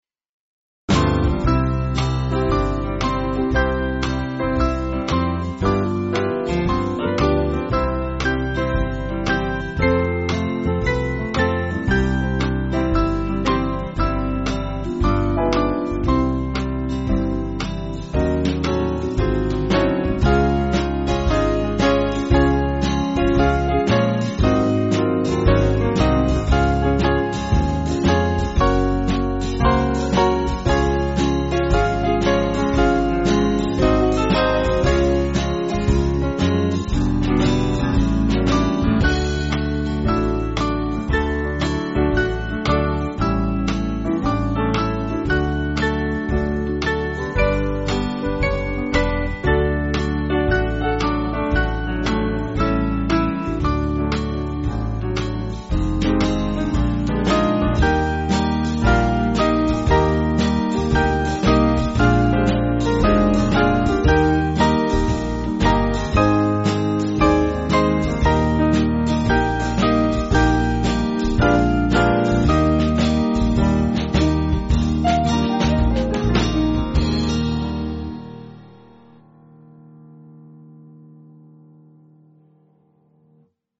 Swing Band
(CM)   4/C-Db-D-Eb